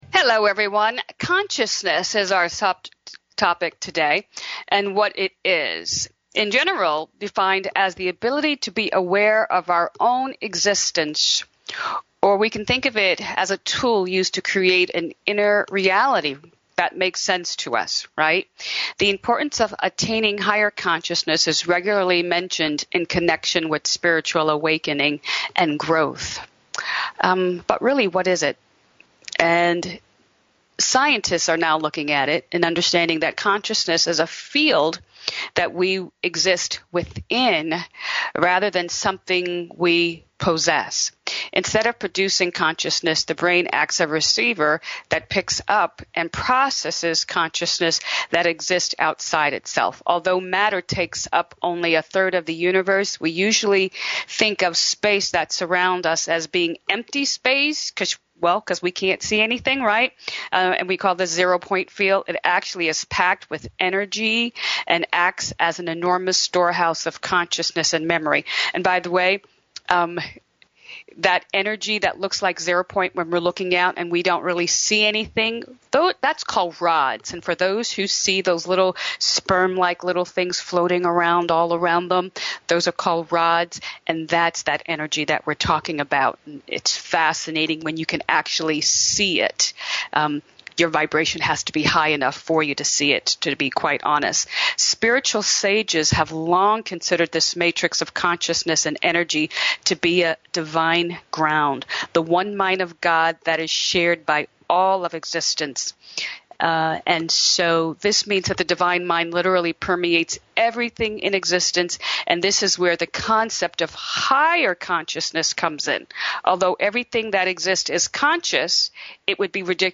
Talk Show Episode, Audio Podcast, WHAT IS CONSCIOUSNESS and Importance of Attaining Higher Consciousness on , show guests , about Consciousness,Existence,Awareness,Reality,Awakening,Spiritual,Growth,Feeling, categorized as Health & Lifestyle,Alternative Health,Philosophy,Psychology,Self Help,Motivational,Spiritual
Each week you’re invited to join in on our upbeat discussion as we look deeper into spiritual based principles that can change your life.